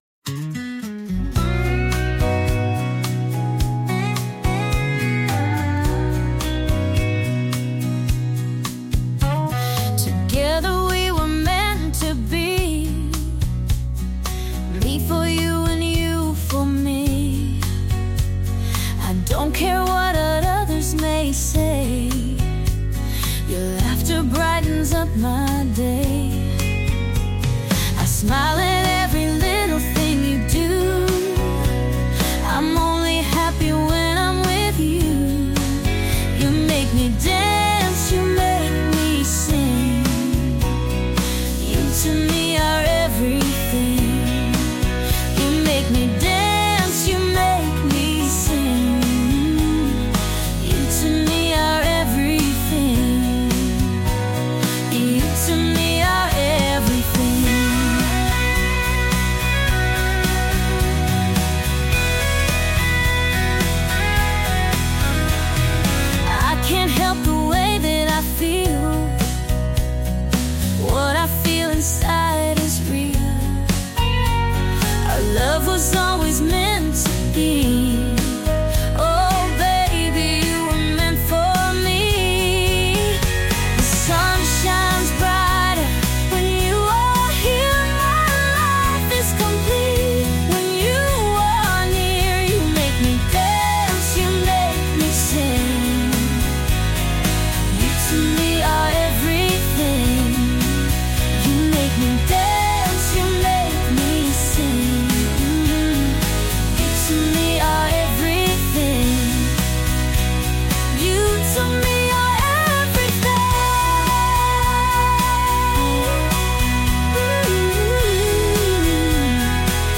female led Country